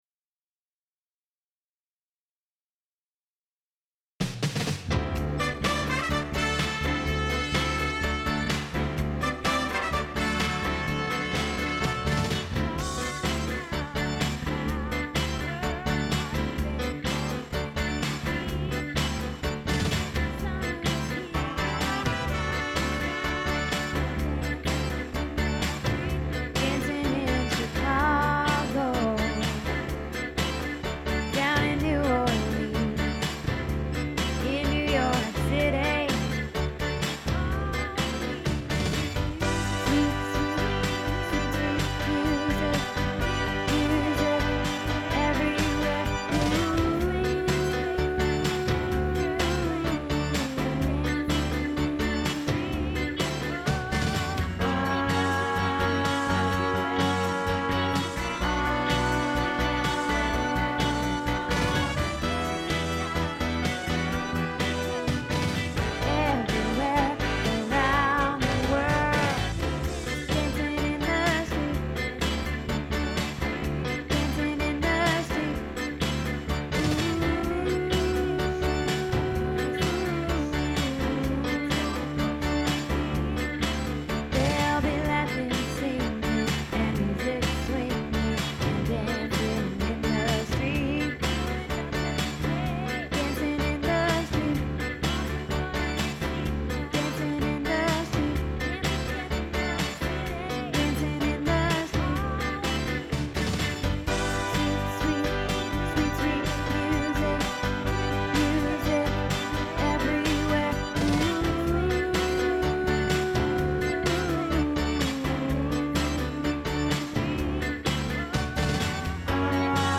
Dancing in the Streets Bass